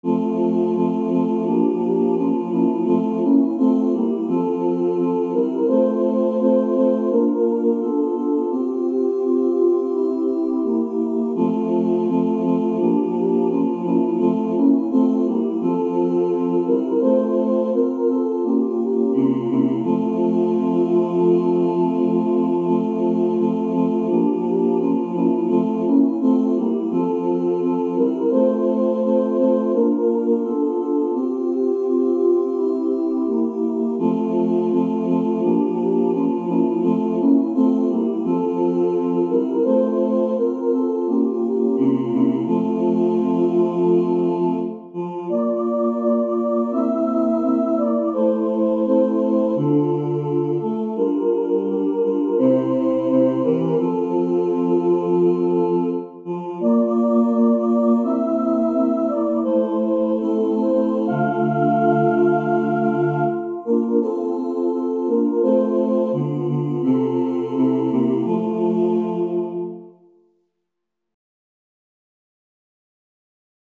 SATB, SATB quartet